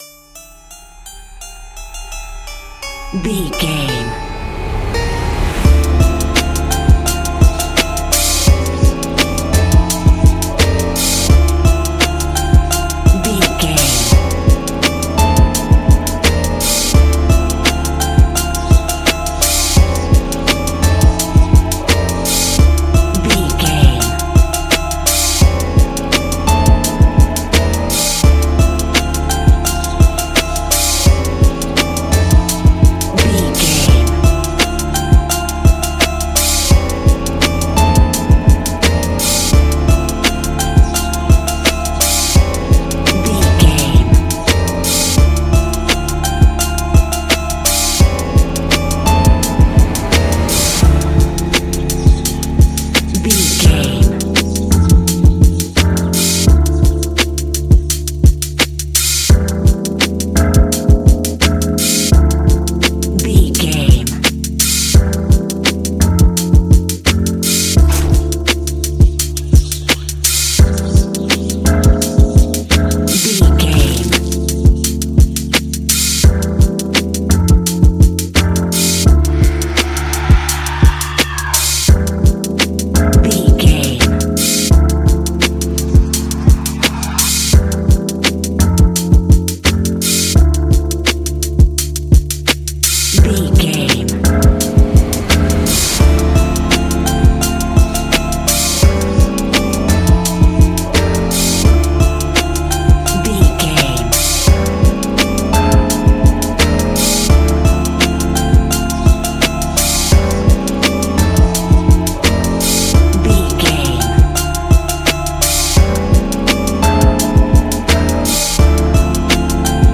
Ionian/Major
D
chilled
laid back
Lounge
sparse
new age
chilled electronica
ambient
atmospheric